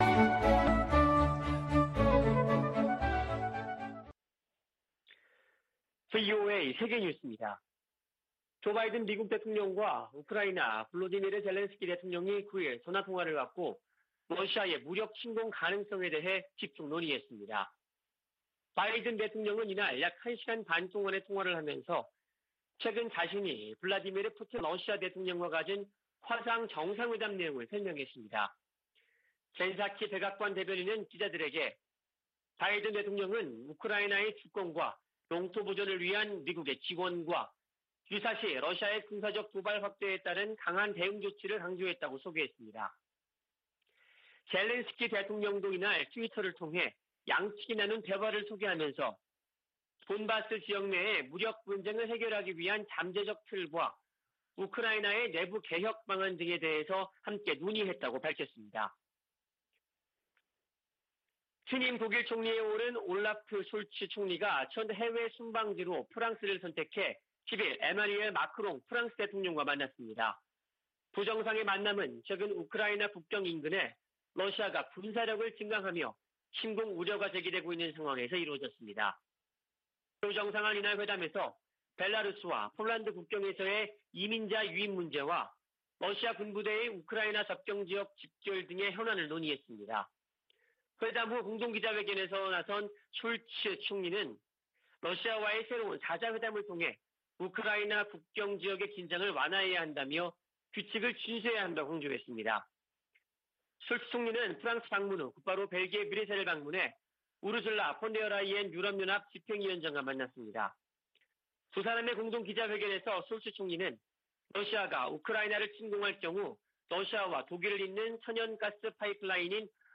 VOA 한국어 아침 뉴스 프로그램 '워싱턴 뉴스 광장' 2021년 12월 11일 방송입니다. 조 바이든 대통령은 세계 민주주의와 인권이 도전에 직면했다며 지속적인 노력이 필요하다고 강조했습니다. 올해도 유엔 안전보장이사회에서 북한 인권에 대한 공개 회의가 열리지 않는다고 미 국무부가 밝혔습니다. 북한에 각종 디지털 기기들이 보급되면서 외부 정보와 문화에 귀기울이는 젊은이들이 늘고 있습니다.